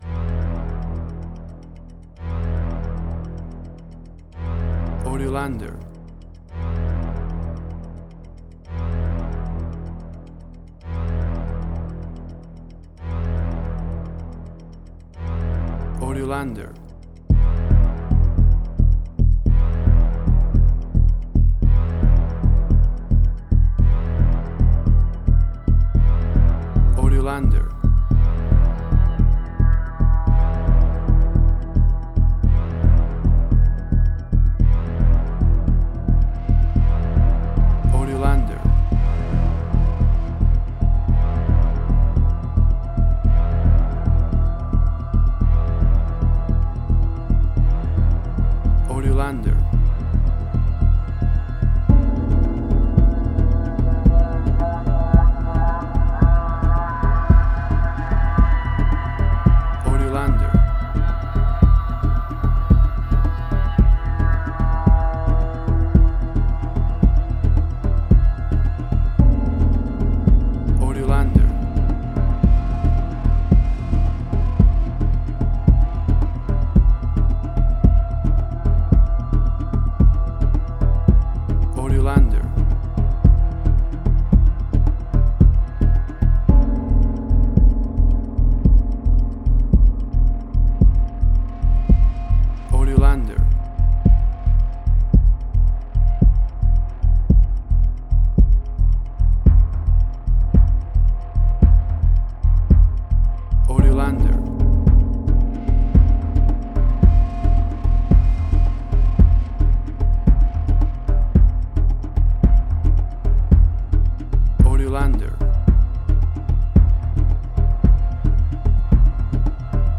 Modern Science Fiction Film, Similar Tron, Legacy Oblivion.
Tempo (BPM): 111